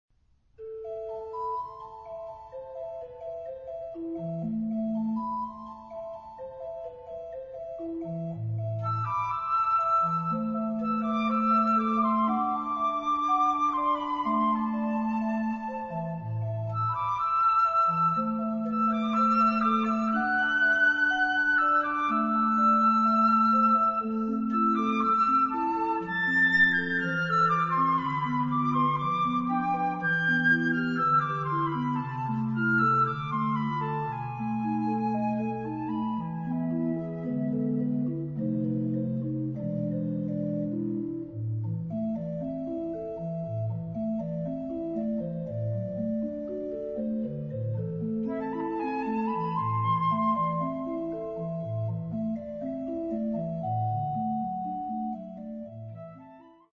for flute and choir organ
場所：聖ボニファシウス教会（オランダ、メデンブリック）